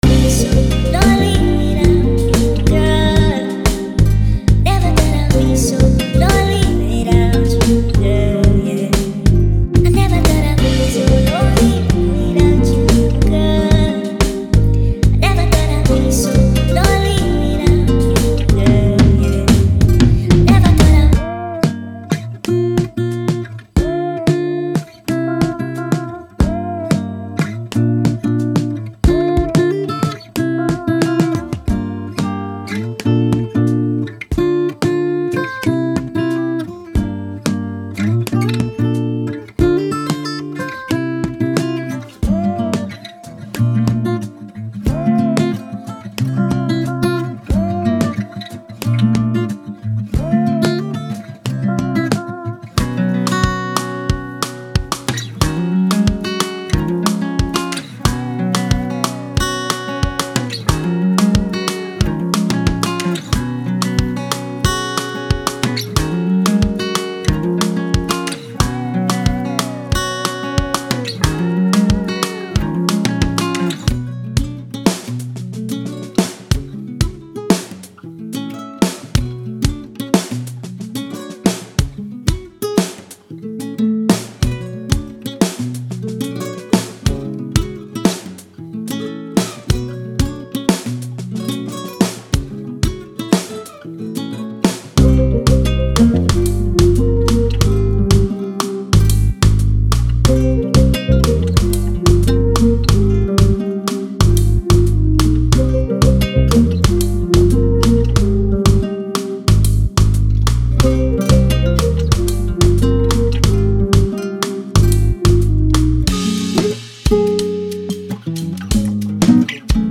groove-rich inspiration.
– 25+ Guitar Loops
– 80+ drum and percussion loops